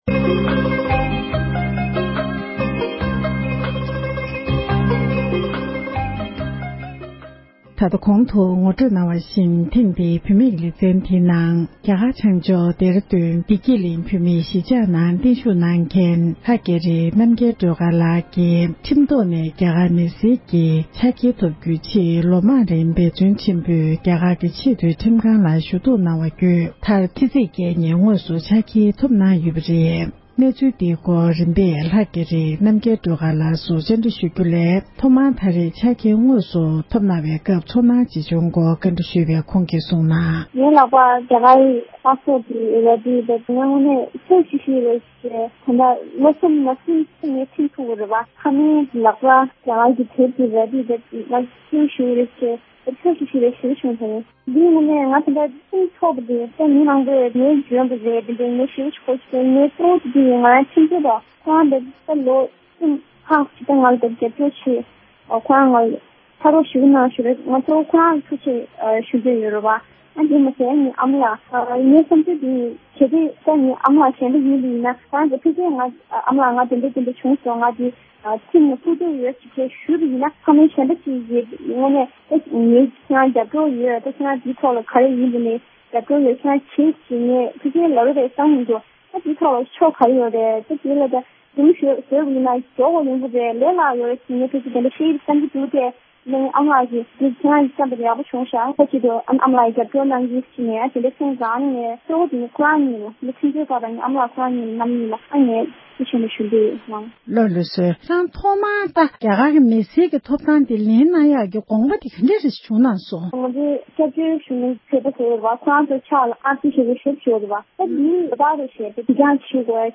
འབྲེལ་ཡོད་མི་སྣར་གནས་འདྲི་ཞུས་པ་ཞིག་ལ་གསན་རོགས་ཞུ༎